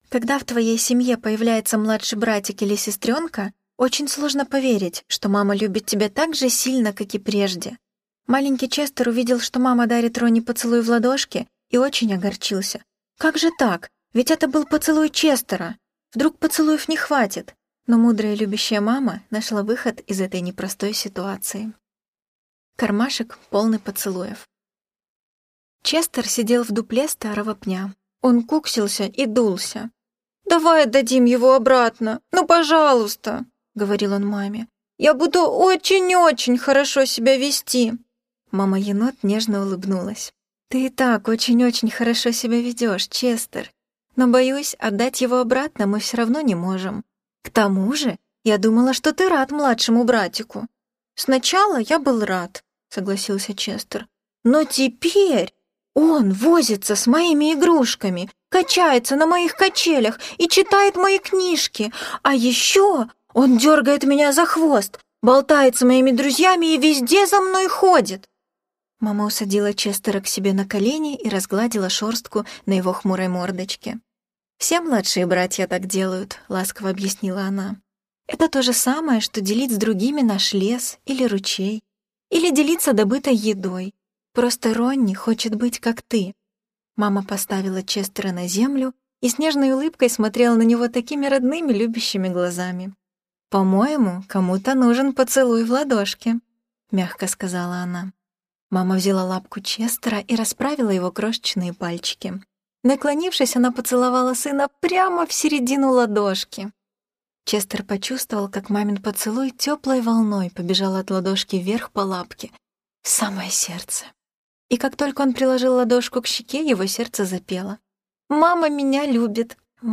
Кармашек, полный поцелуев - аудиосказка - слушать онлайн